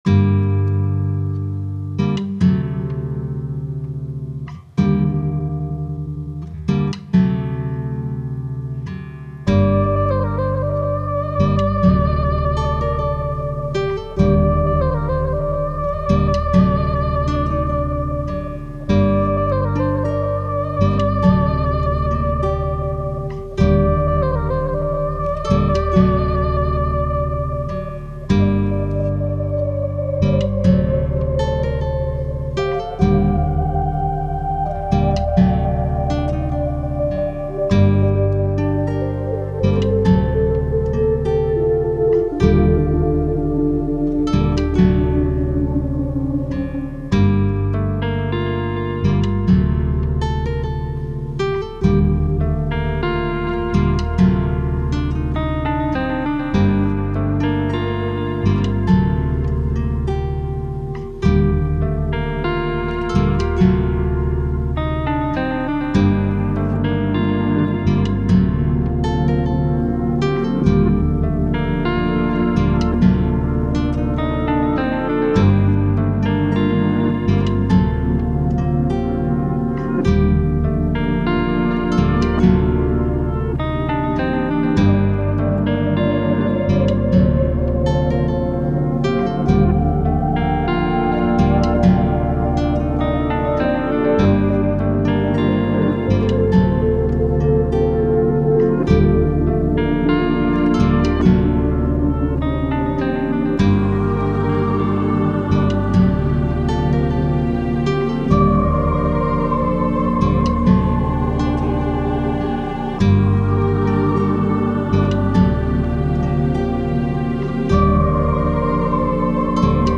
悲しい回想シーンをイメージして作った楽曲。 全体的に残響を強くすることで過去の回想の雰囲気を出している。
タグ: 切ない 寂しい/悲しい 暗い コメント: 悲しい回想シーンをイメージして作った楽曲。